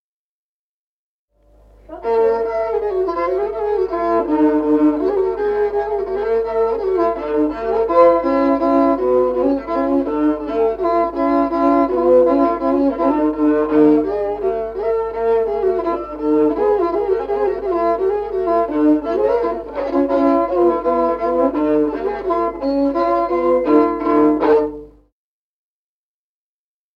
Музыкальный фольклор села Мишковка «Шахтёр», партия 2-й скрипки.